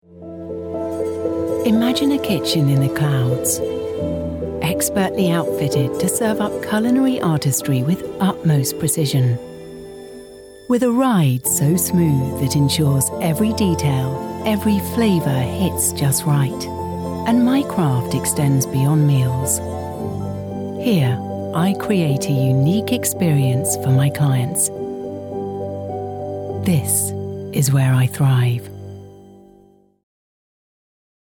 British English Female Voice Over Artist
Assured, Authoritative, Confident, Conversational, Corporate, Deep, Engaging, Friendly, Funny, Gravitas, Natural, Posh, Reassuring, Smooth, Upbeat, Versatile, Warm
Microphone: Neumann TLM 103
Audio equipment: Focusrite clarett 2 PRE, Mac, fully sound-proofed home studio